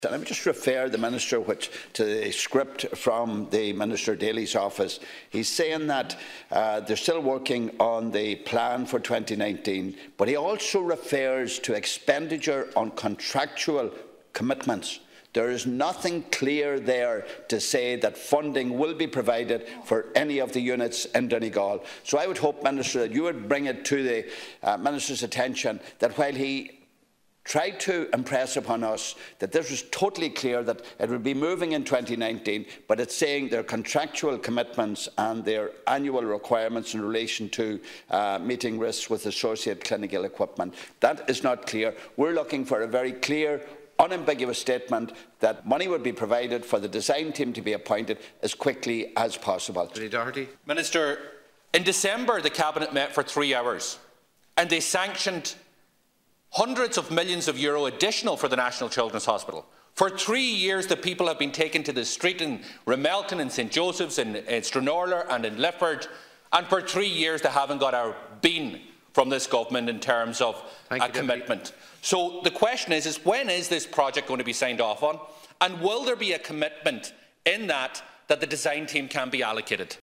In a cross-party representation in the Dail yesterday, Donegal Deputies Pat the Cope Gallagher, Pearse Doherty and Charlie McConalogue pressed Minister Finian McGrath for a commitment that work at the hospitals would commence as soon as possible.
Deputies Gallagher and Doherty told Minister McGrath that the communities of Stranorlar, Ramelton and Lifford have waited long enough: